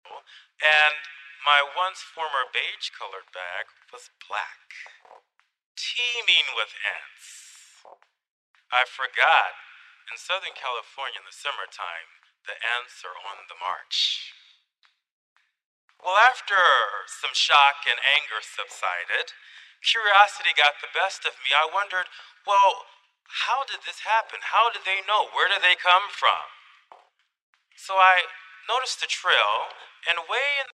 Here's the buzz noise analyzed. It takes up the same spectrum as the human voice, which means if you try to eq out the buzz, the lecture will be affected as well.